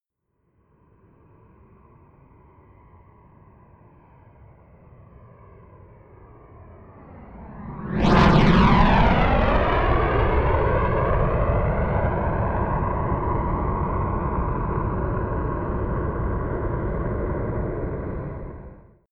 Gemafreie Sounds: Flugzeug - Jet